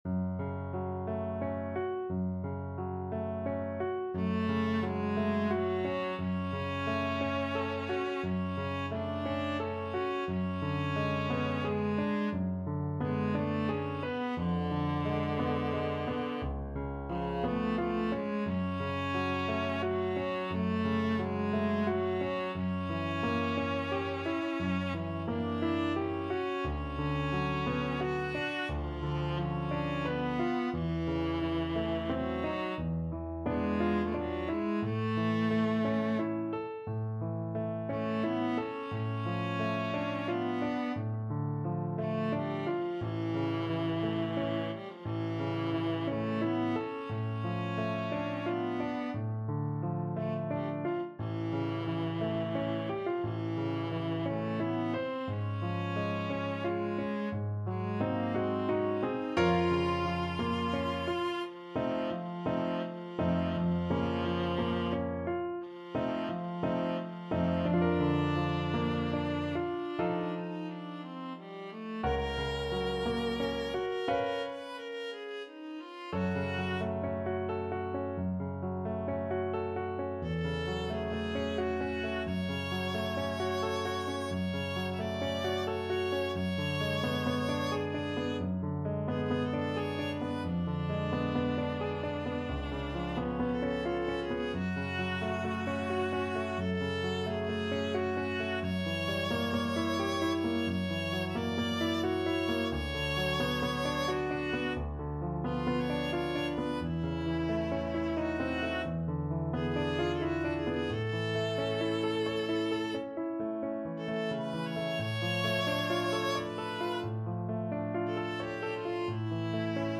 Viola
F# minor (Sounding Pitch) (View more F# minor Music for Viola )
~ = 88 Malinconico espressivo
3/4 (View more 3/4 Music)
Classical (View more Classical Viola Music)